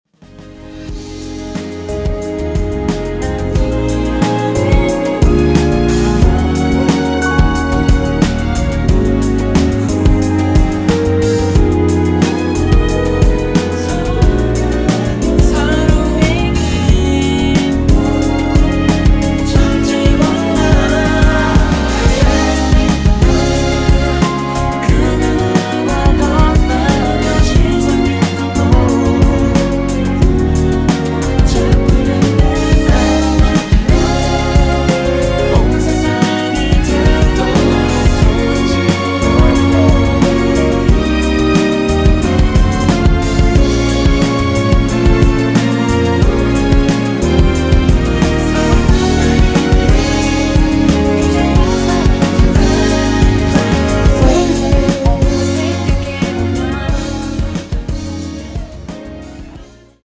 원키에서(-2)내린 코러스 포함된 MR입니다.
Db
앞부분30초, 뒷부분30초씩 편집해서 올려 드리고 있습니다.
중간에 음이 끈어지고 다시 나오는 이유는